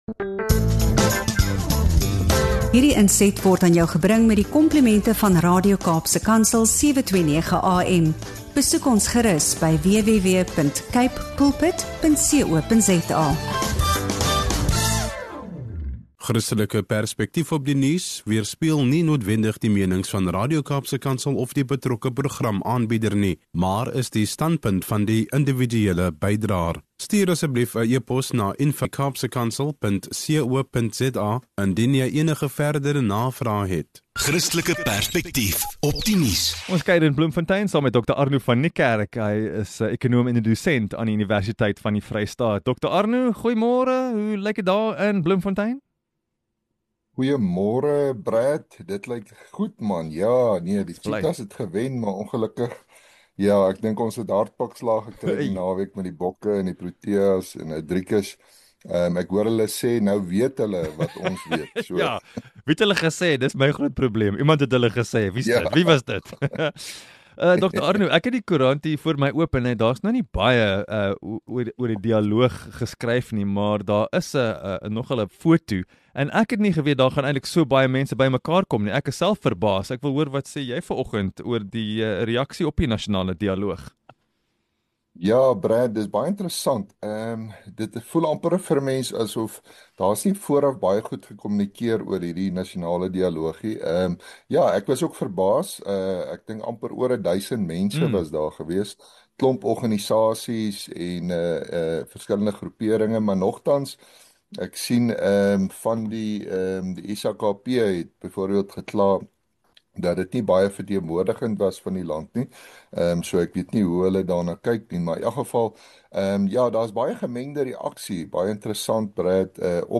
Hierdie gesprek, uitgesaai op Radio Kaapse Kansel, bied insig in die gemengde reaksies rondom die nasionale dialoog en die toenemende probleem van geweldsmisdaad in Suid-Afrika.